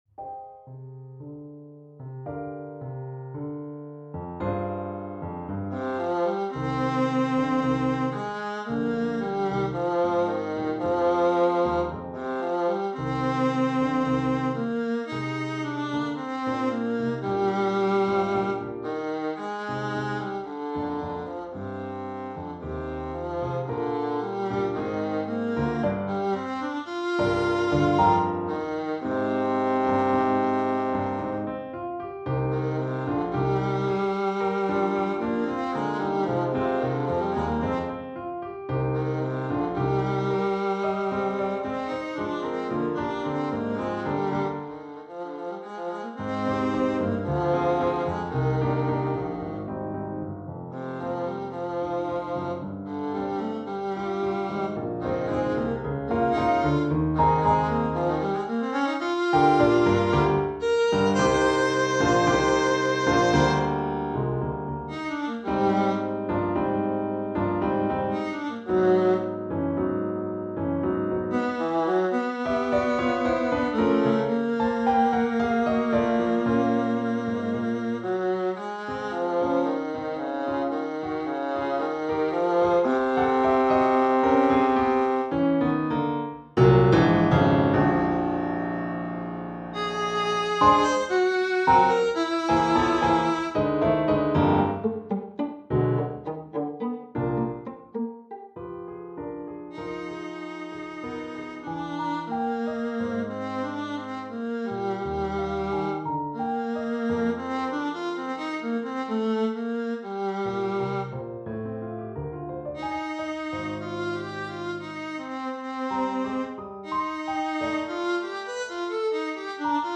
for Viola & Piano